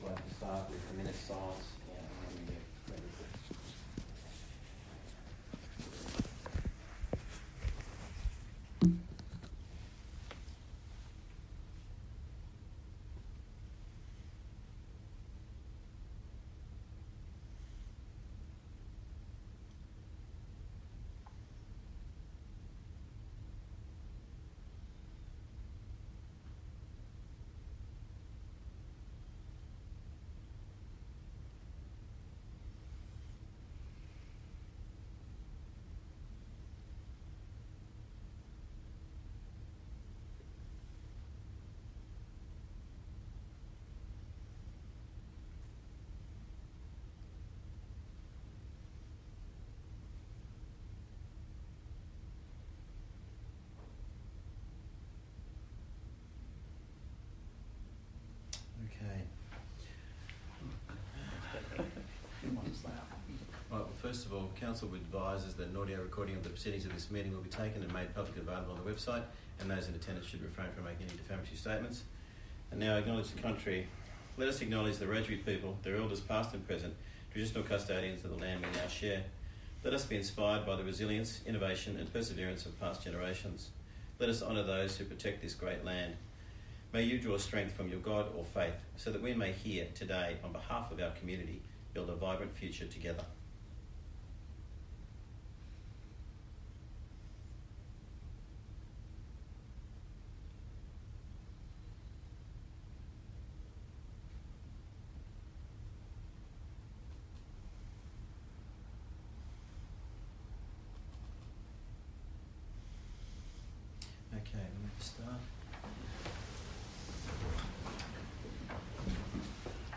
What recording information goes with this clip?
Bland Shire Council Chambers, 6 Shire St, West Wyalong, 2671 View Map